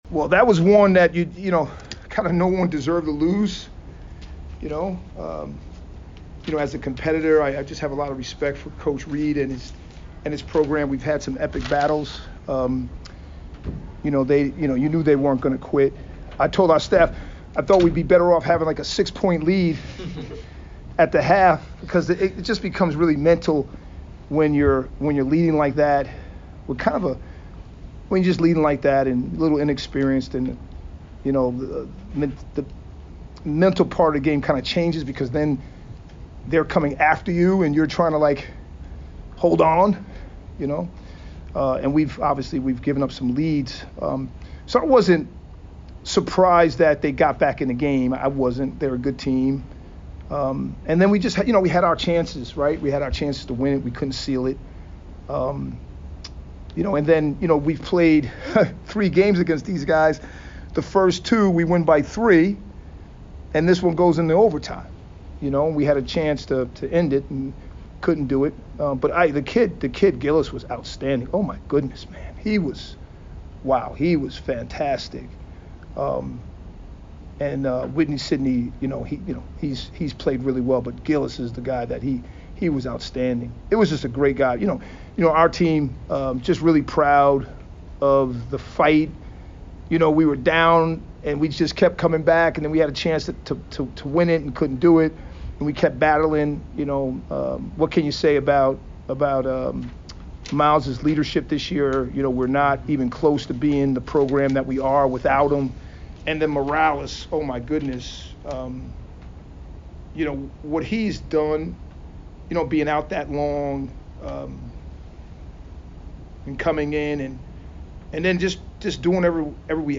Lehigh Postgame Interview